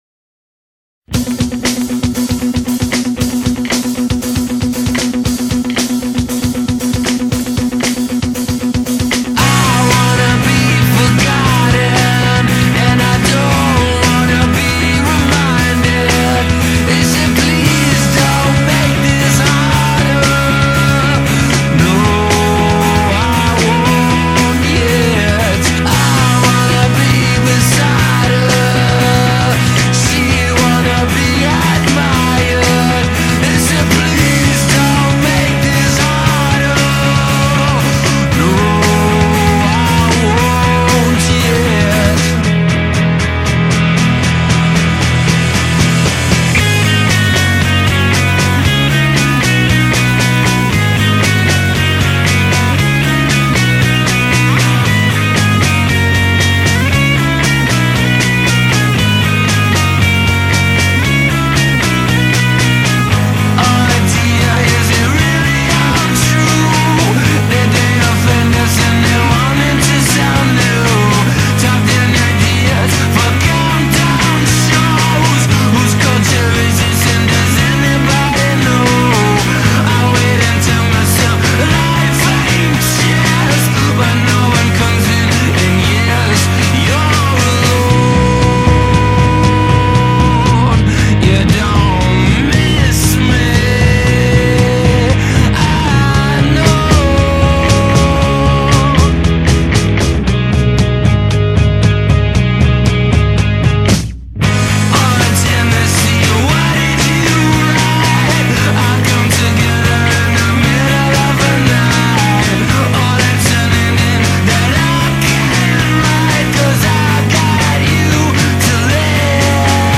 Indie Rock, Garage Rock Revival, New Wave